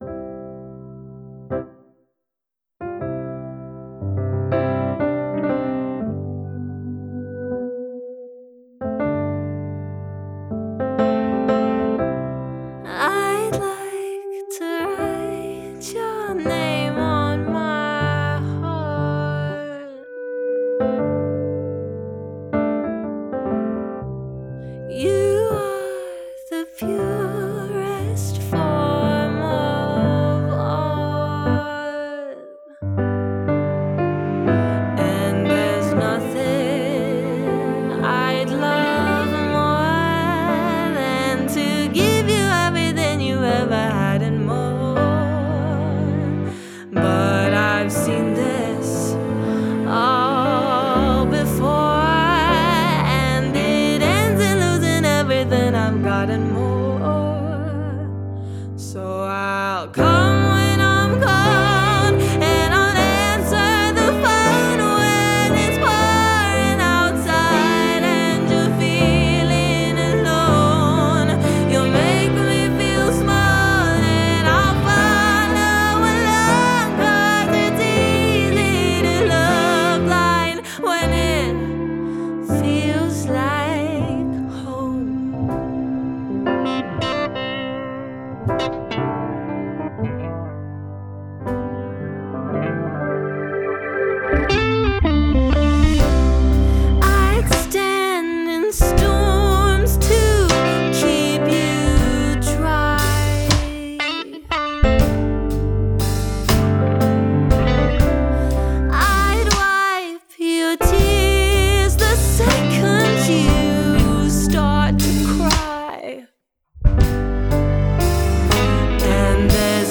Duo & Band Vocalist
Energetic, Impressive, Modern..
Band | Blues | Duo | Jazz | Pop | Rock